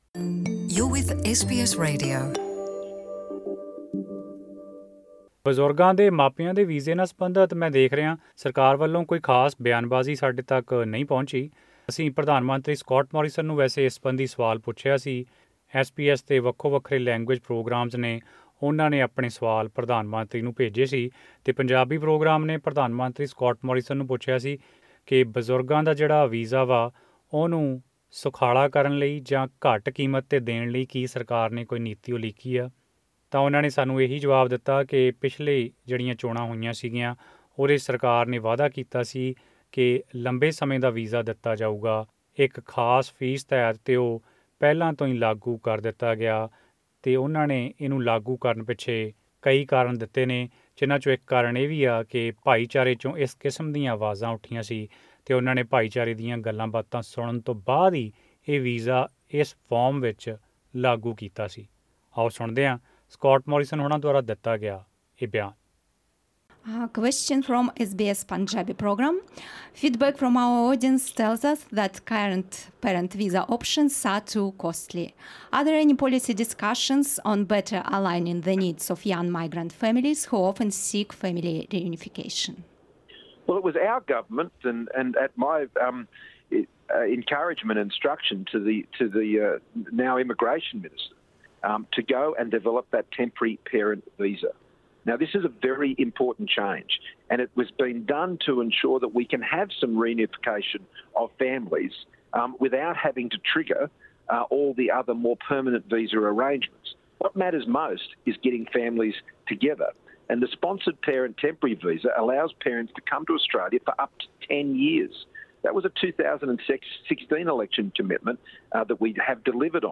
Australian Prime Minister Scott Morrison speaks about the parent visa concerns